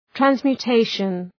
Shkrimi fonetik{,trænzmju:’teıʃən, ,trænsmju:’teıʃən}
transmutation.mp3